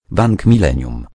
Dźwięki ostrzegawcze Bank Millennium
Dźwięki ostrzegawcze Bank Millennium Pobierz gotowe komunikaty głosowe / alarmy ostrzegawcze w formacie MP3.